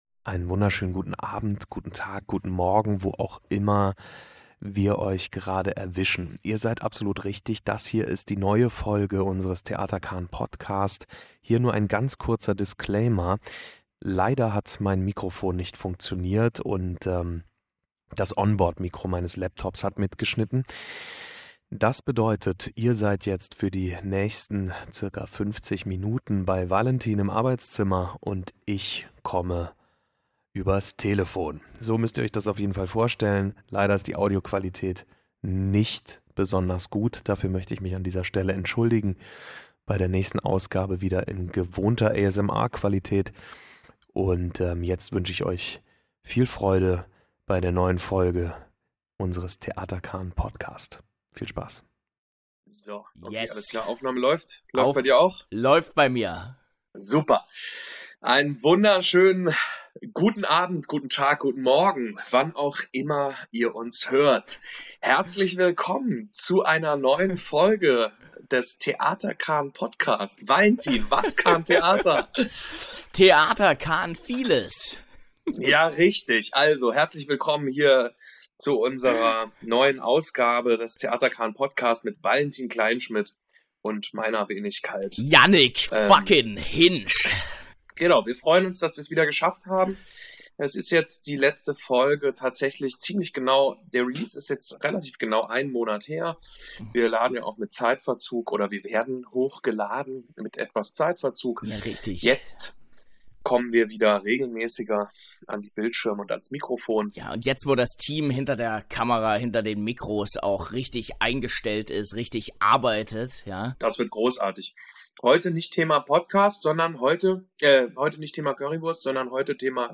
unterhalten sich die Schauspieler